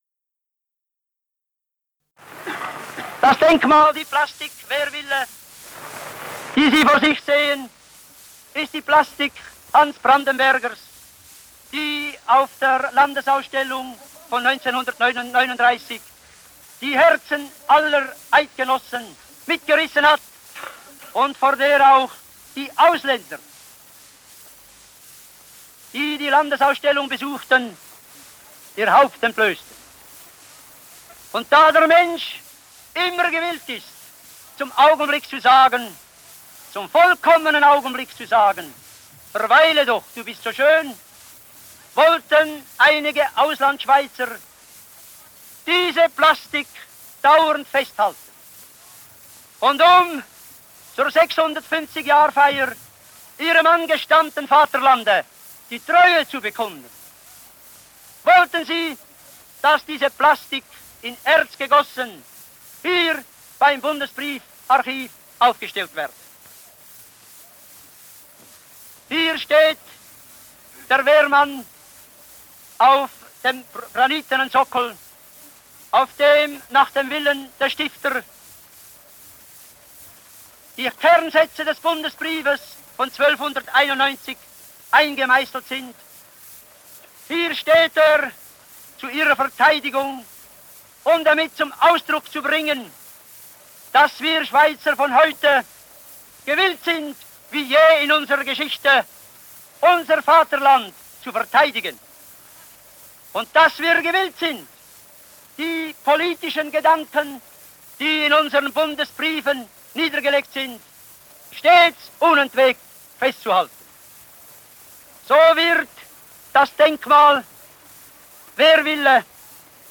Reportage über die Landesausstellung 1939